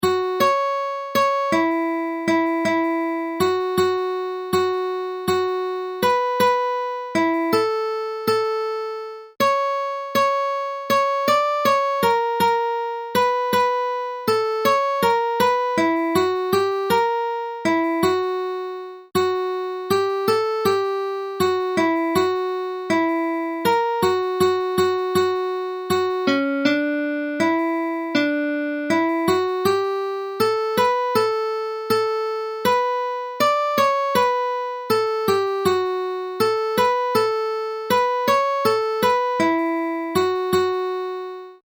The following "Hymn to the Muse" by Mesomedes is in the Lydian mode (soft or round Lydian, since the B is flatted), which was the most popular mode at that time. The style is intentionally archaic.
I have transposed the music to D major, which is more convenient for recorders, tin whistles, and similar instruments; transposing to other keys is left as an exercise for the reader!
Instrumental performance on lyre (brisk tempo) [820KB].